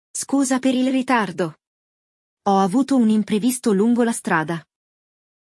O episódio traz um diálogo entre dois amigos que combinam de se encontrar para comer.